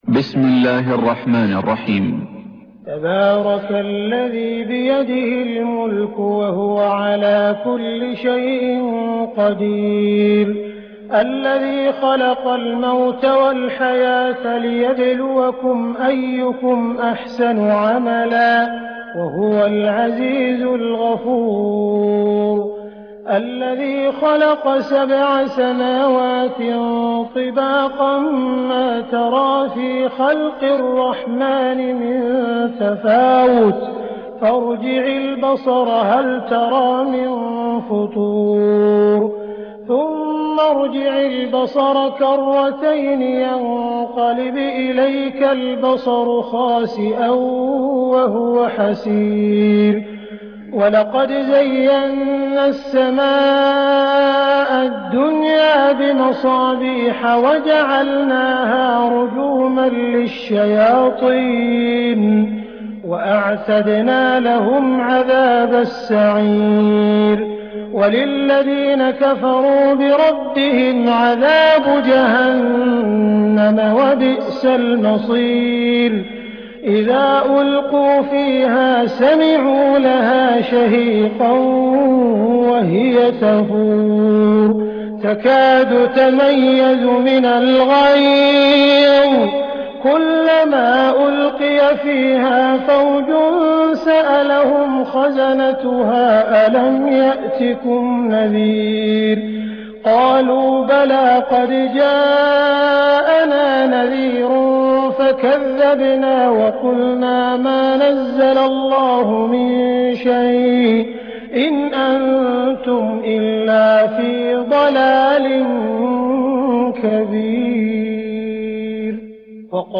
récitation- Hafidh Abd er-Rahmân Sudais (qdlfm) - 5 524 ko ;
067-Surat_Al_Mulk_(La_royaute)_Cheikh_Sudais.rm